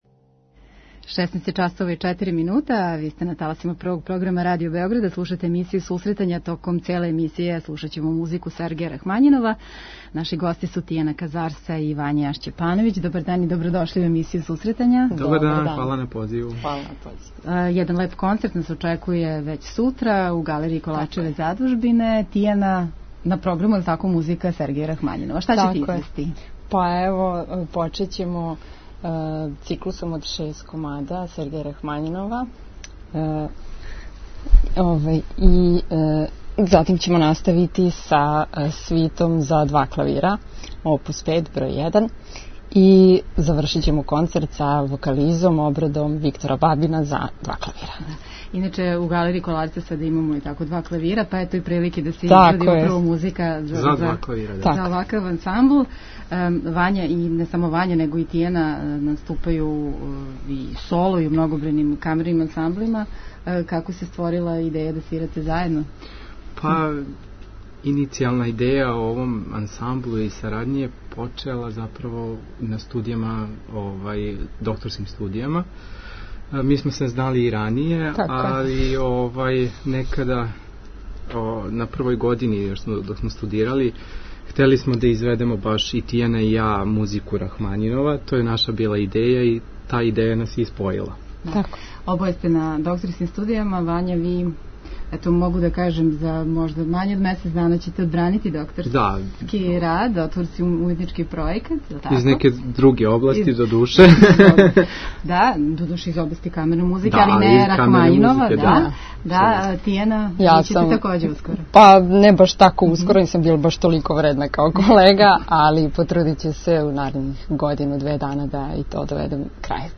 Емисија за оне који воле уметничку музику.